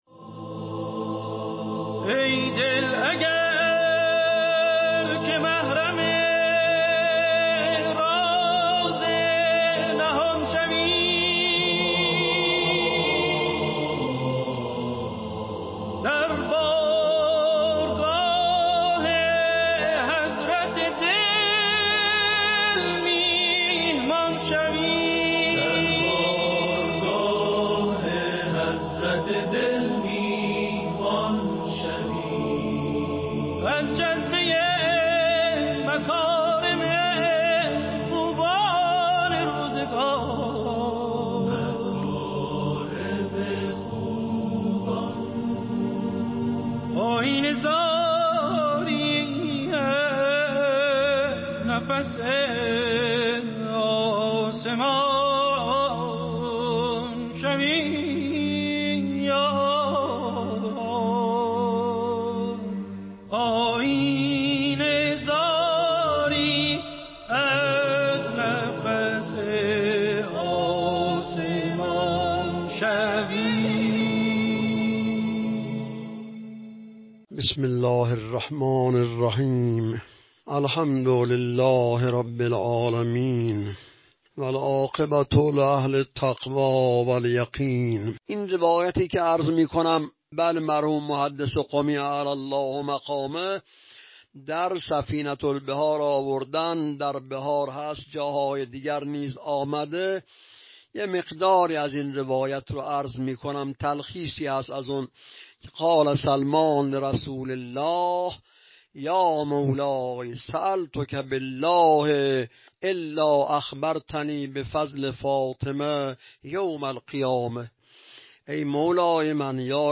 درس اخلاق | چشم‌ها را ببندید؛ دختر محمد (ص) می‌آید